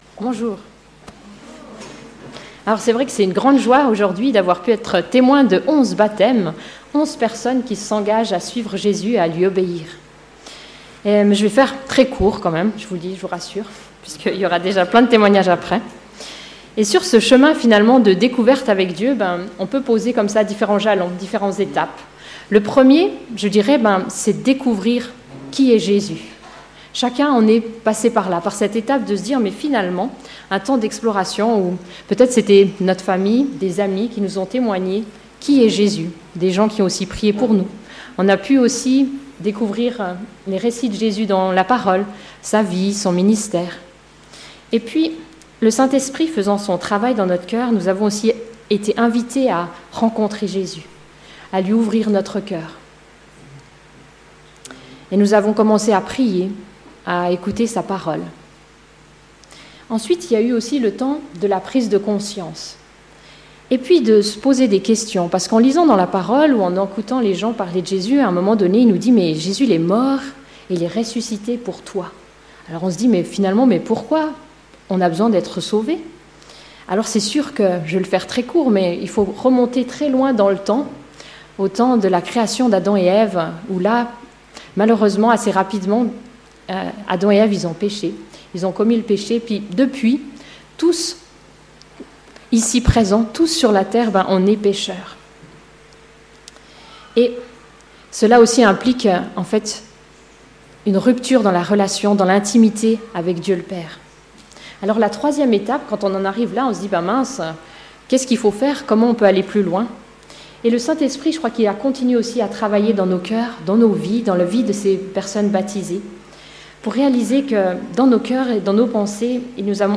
Culte de baptême du 1er juillet 2018
Méditation